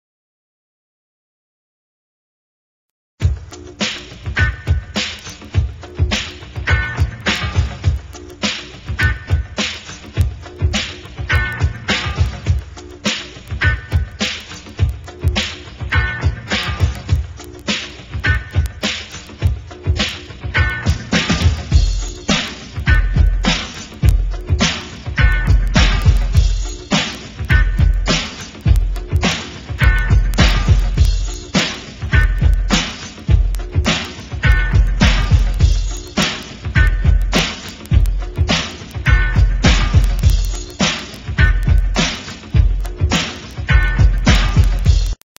HIPHOP, RAP KARAOKE CDs